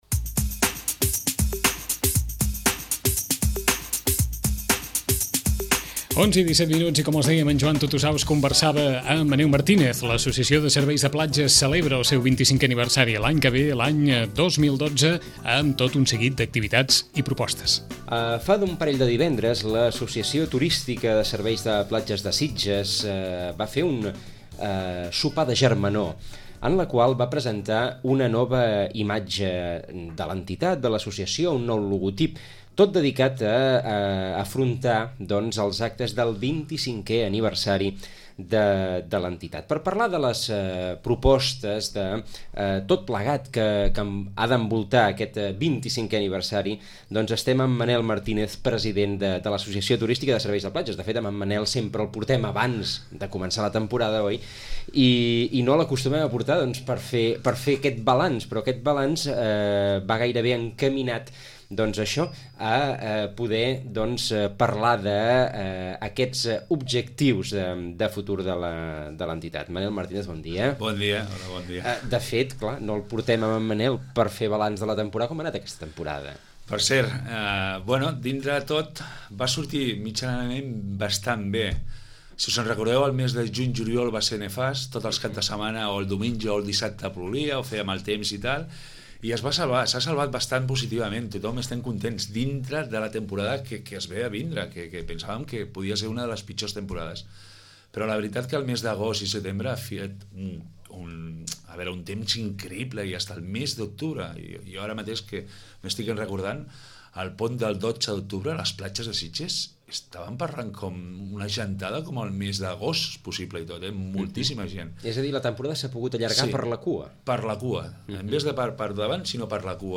Ràdio Maricel. Emissora municipal de Sitges. 107.8FM. Escolta Sitges.